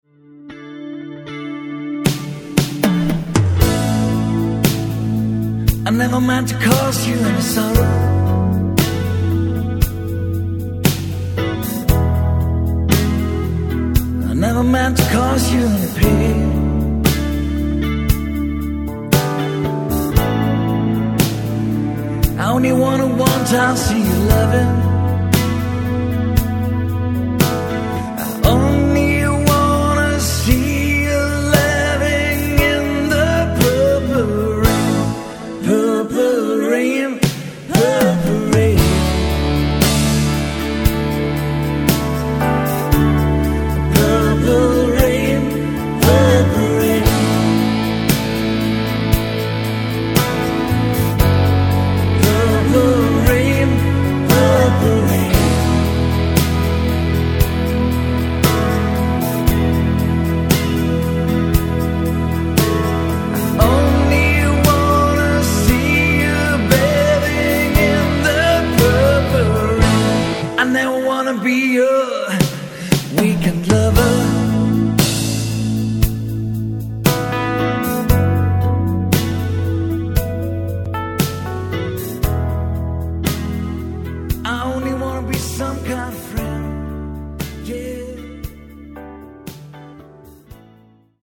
Bass, Guitar, Keys, Vocals
Vocals, Percussion